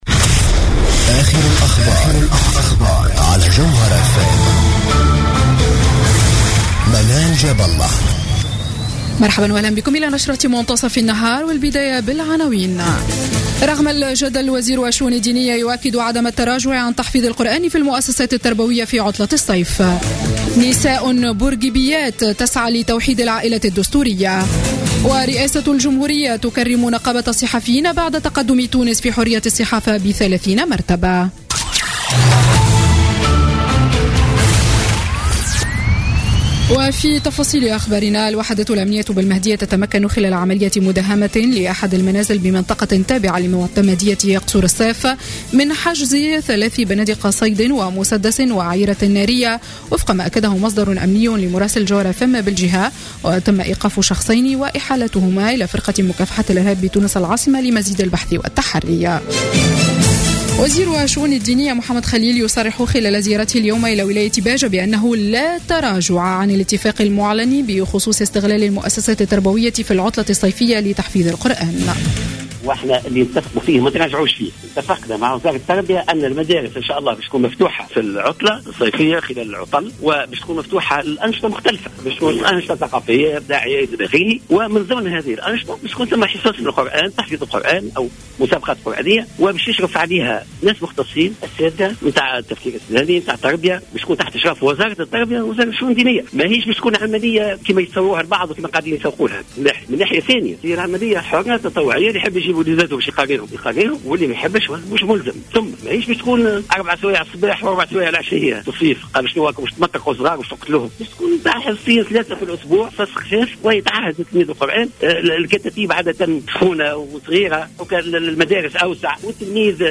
نشرة أخبار منتصف النهار ليوم السبت 23 أفريل 2016